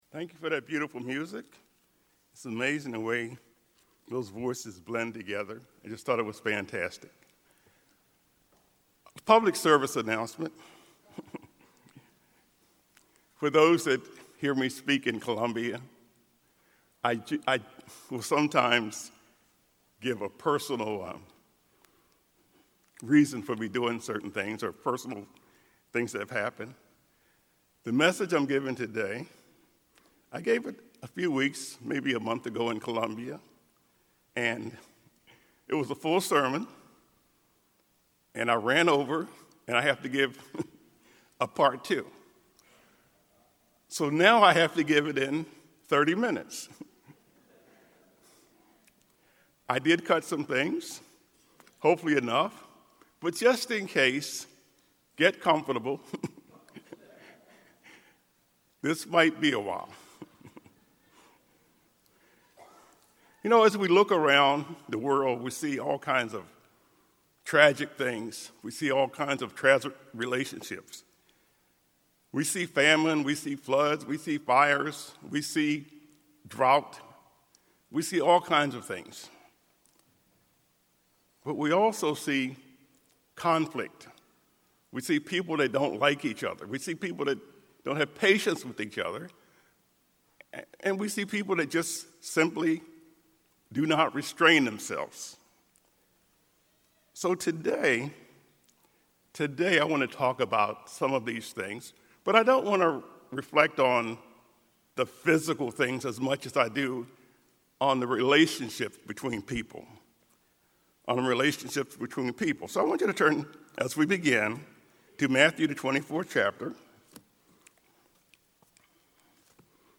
This sermon was given at the Ocean City, Maryland 2022 Feast site.